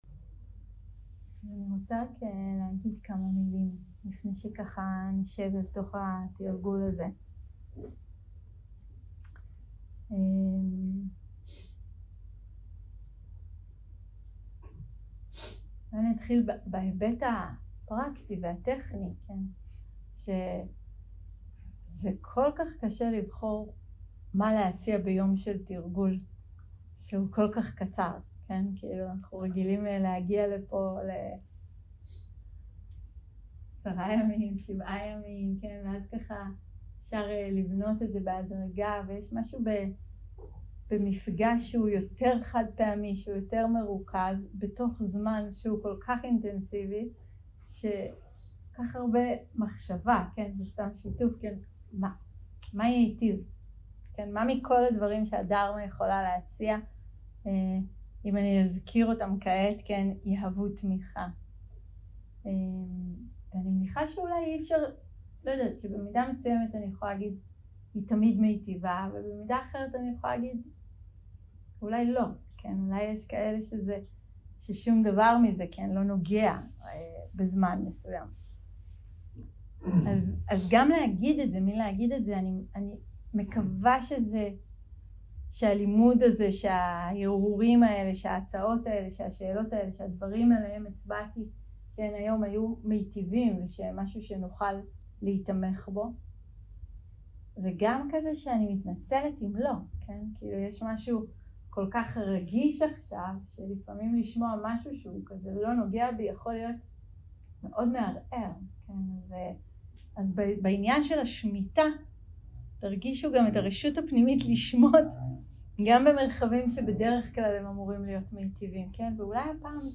הקלטה 4 - צהרים - מדיטציה מונחית - כוונה לטוב
סוג ההקלטה: מדיטציה מונחית